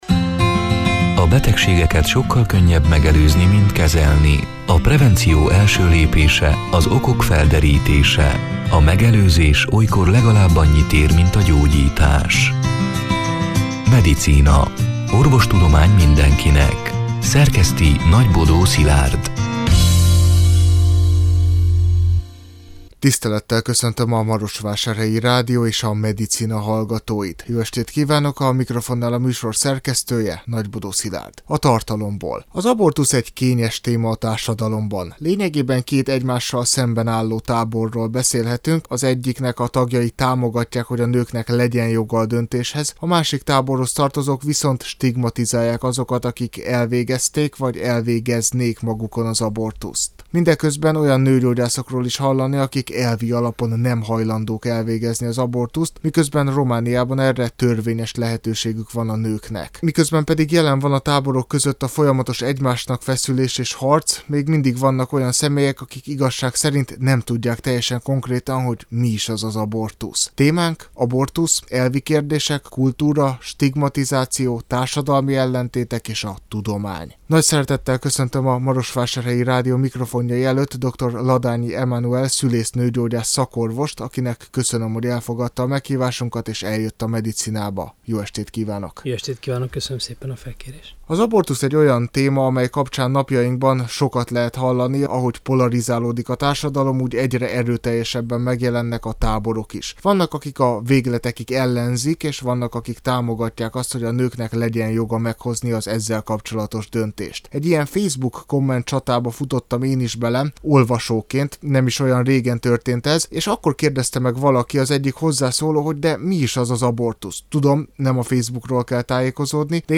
A Marosvásárhelyi Rádió Medicina (elhangzott: 2024. december 11-én, szerdán este nyolc órától) c. műsorának hanganyaga: